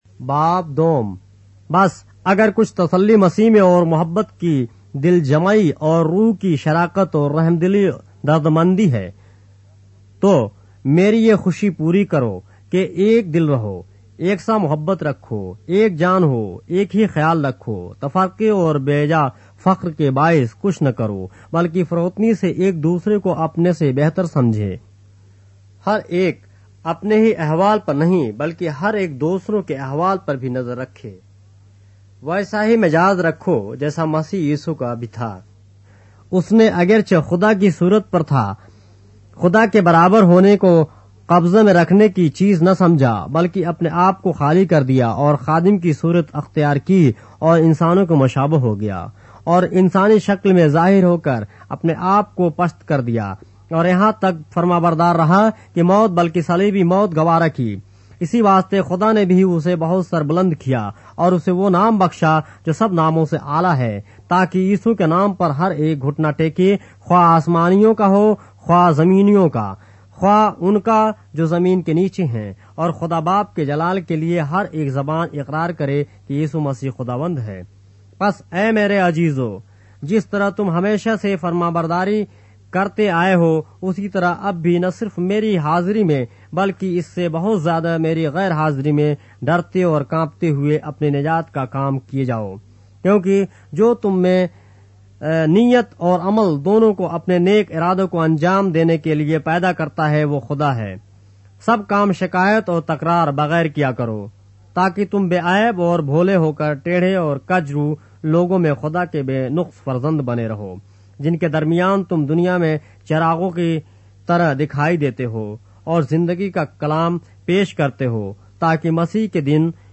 اردو بائبل کے باب - آڈیو روایت کے ساتھ - Philippians, chapter 2 of the Holy Bible in Urdu